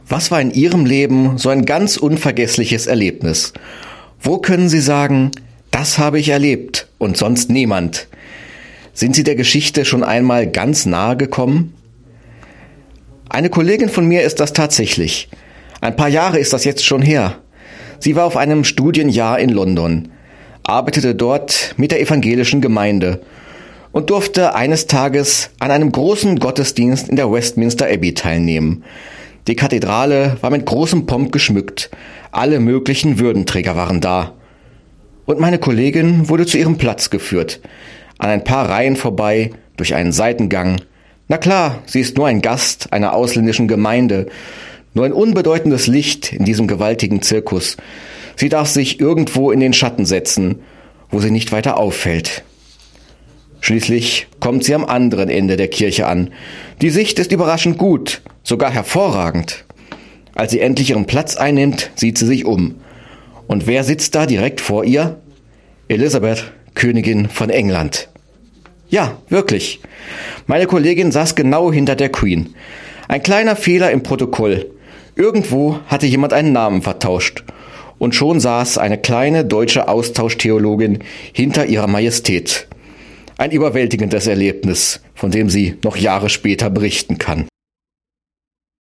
Radioandacht vom 14. Dezember